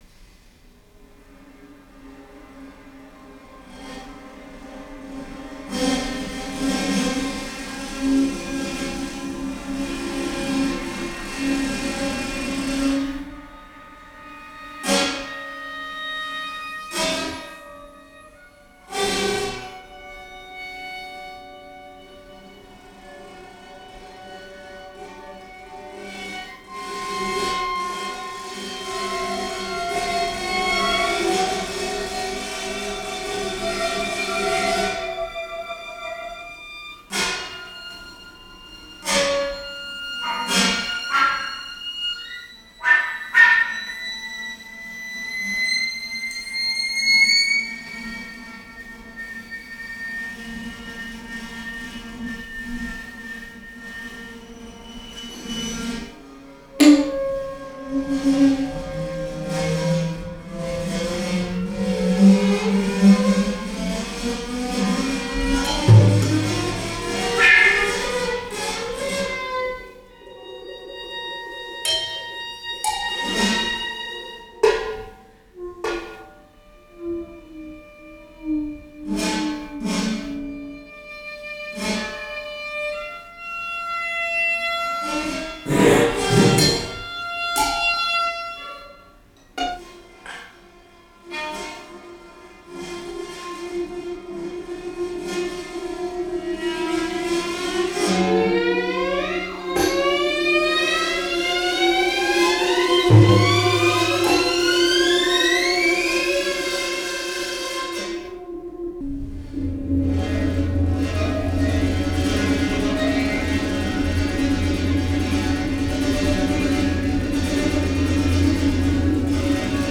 flûte
harpe
violon
batterie
composition & batterie
Idiolecte où le souffle, les phonèmes, les mots et les sons indéterminés des instruments nous immergent  dans un monde poétique.